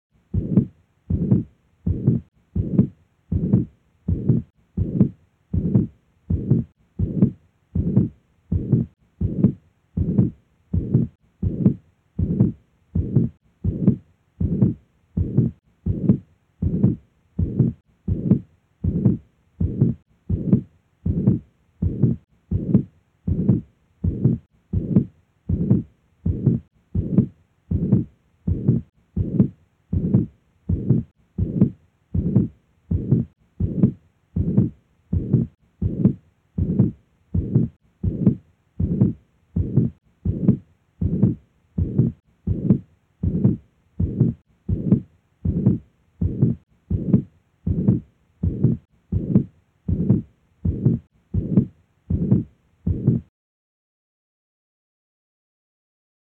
Mitral Regurgitation